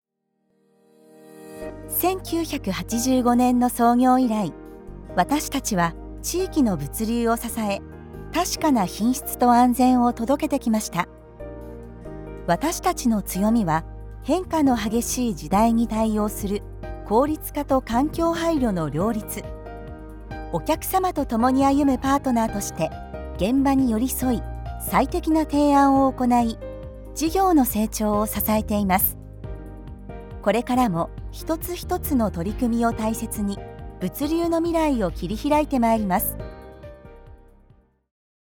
Démo commerciale
Vidéos d'entreprise
Microphone | Audio Technica AT4040